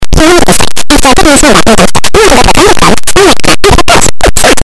It's all peaky and distroted.